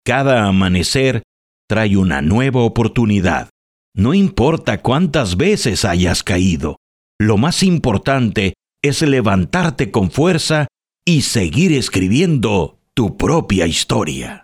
Audio Book Voice Over Narrators
Spanish (Mexican)
Adult (30-50) | Older Sound (50+)
0302Narracion.mp3